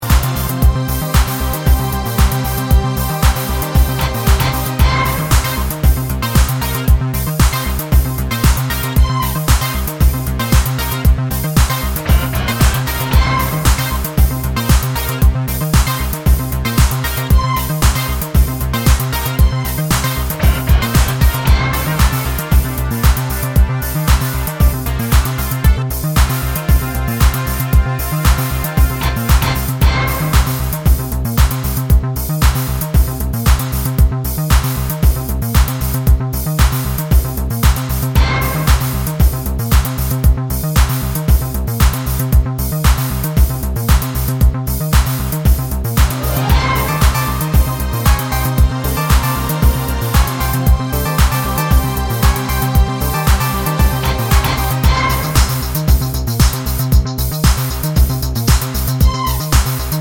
No Vocals At All Pop (1990s) 3:33 Buy £1.50